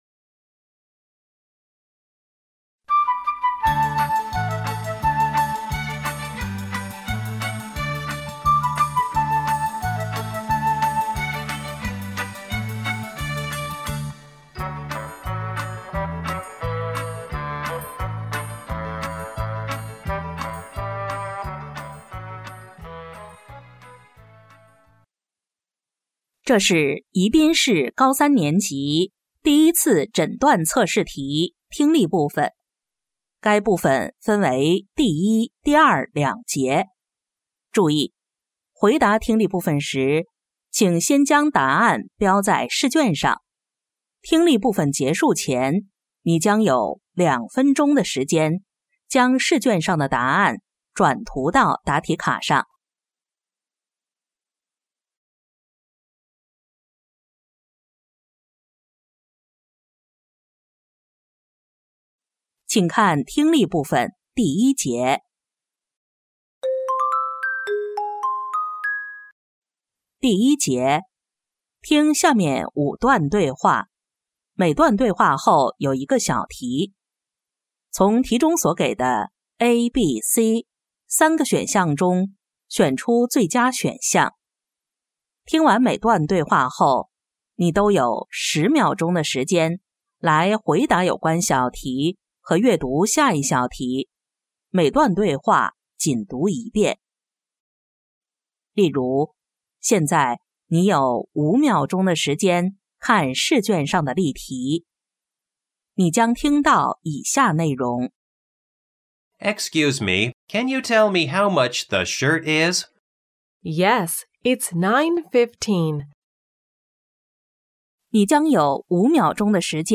2025届宜宾一诊英语听力.mp3